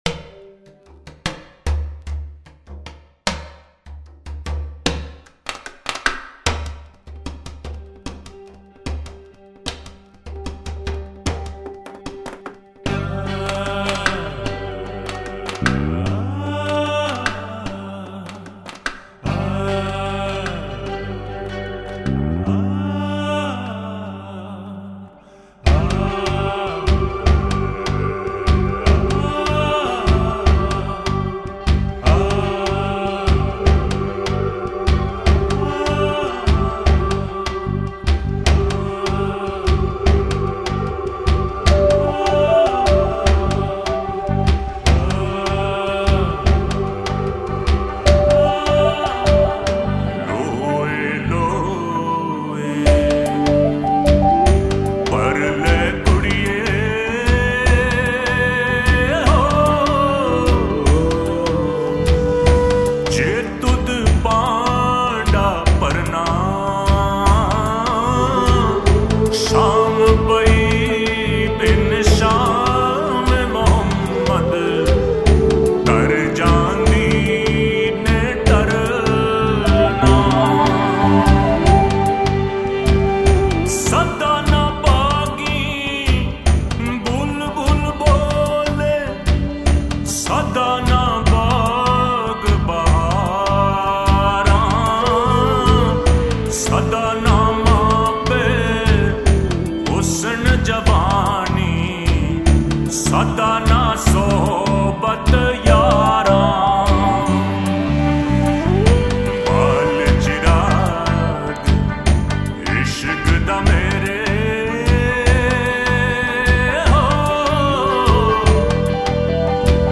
Download 8000+ Sufi MP3 Songs, Books & Art free